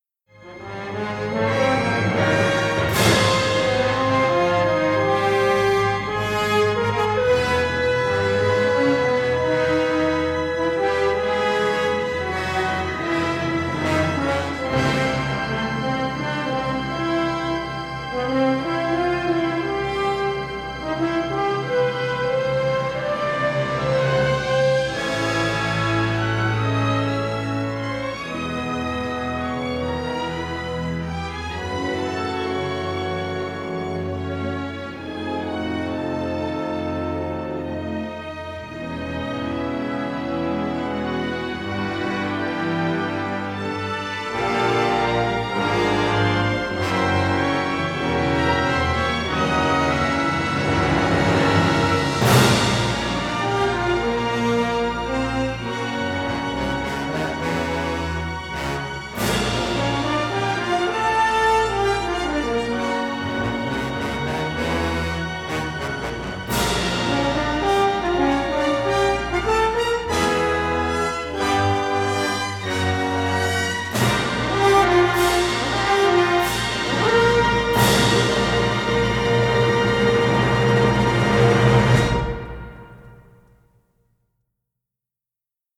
rich symphonic score
three-channel stereo scoring session masters